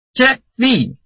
臺灣客語拼音學習網-客語聽讀拼-饒平腔-入聲韻
拼音查詢：【饒平腔】zheb ~請點選不同聲調拼音聽聽看!(例字漢字部分屬參考性質)